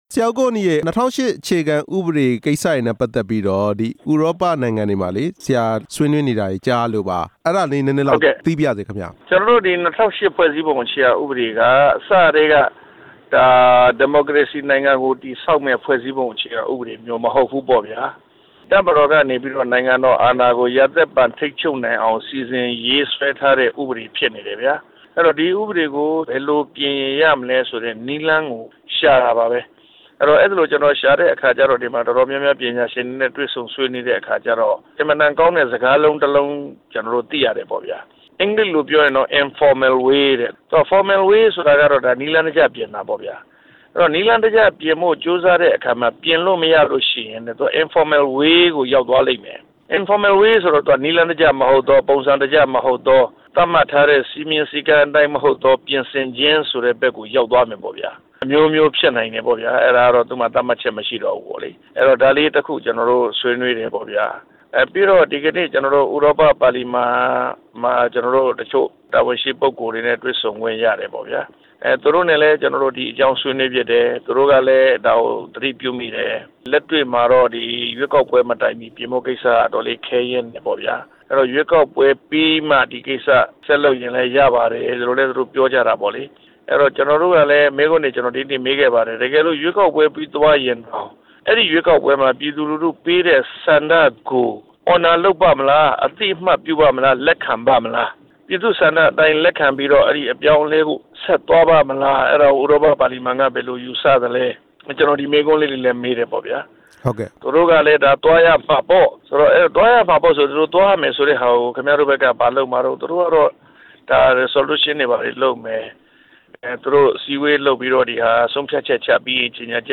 ဆက်သွယ်မေးမြန်းထားတာ